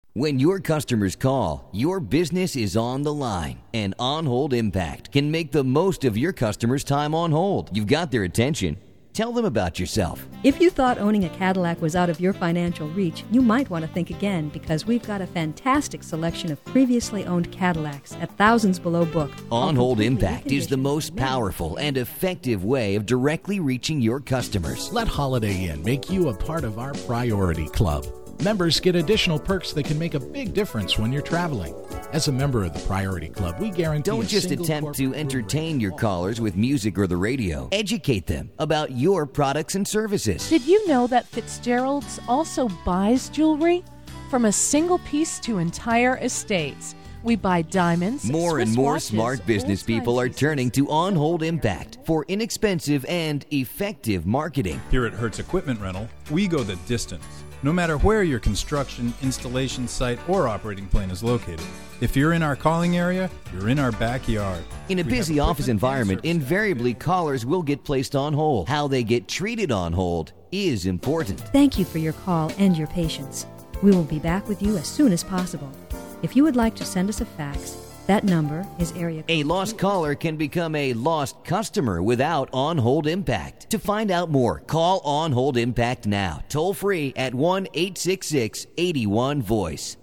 Male and female voices are available.
Messages are written and narrated specifically to convey appreciation to your customers, while motivating them to ask questions about your products and services.
We can provide you with practically any style of background music: contemporary, classical, jazz, country, holiday, and more.
DemoOnHoldMP.mp3